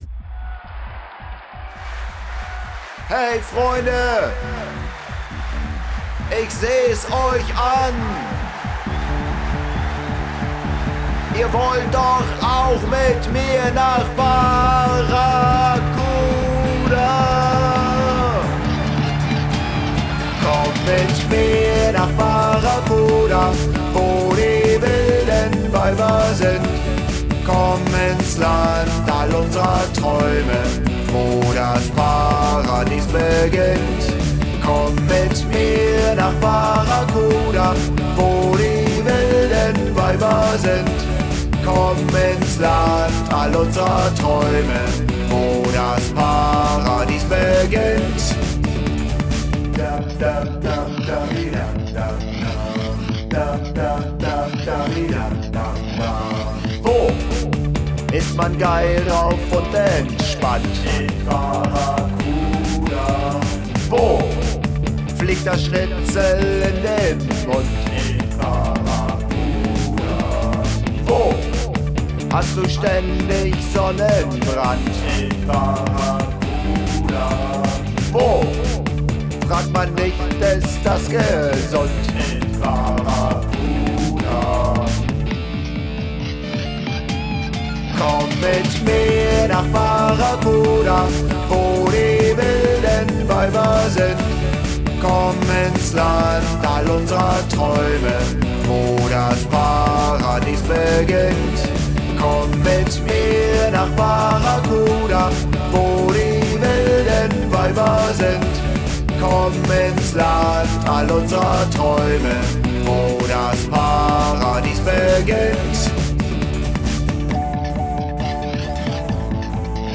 Ich habe auch mal ge-melodynet, in der Sample-Sammlung nach wummrigen Drums gesucht und noch das eine oder andere hier und da: Dein Browser kann diesen Sound nicht abspielen.